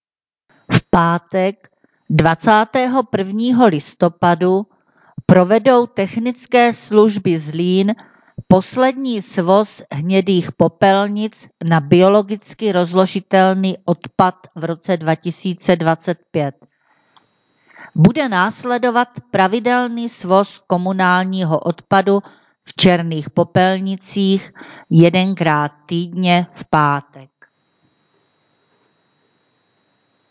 Hlášení místního rozhlasu
Samotné hlášení provádí pracovníci kanceláří místních částí ze svých pracovišť.